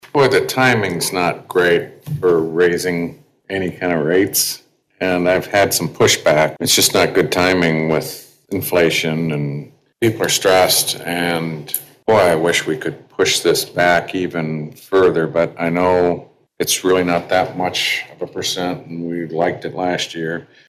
Council member Randall Hazelbaker said his constituents are not happy about the higher rates.